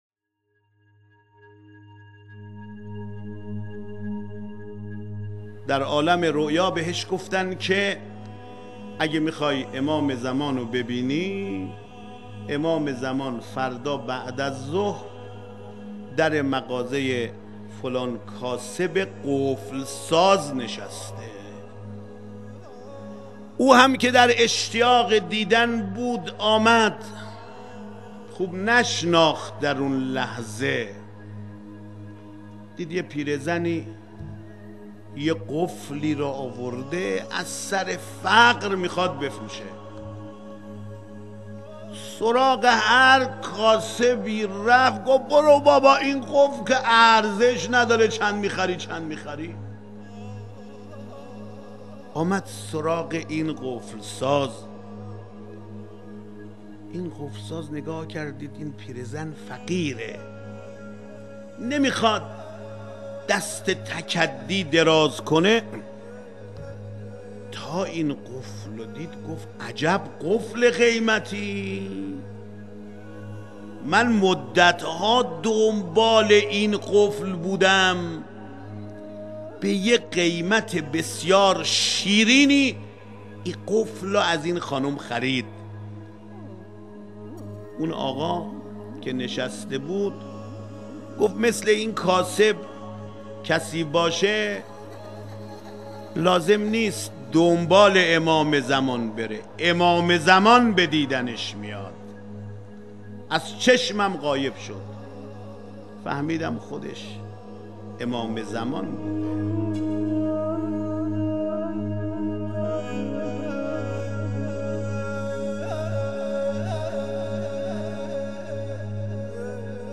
فرازی از سخنرانی آیت الله خاتمی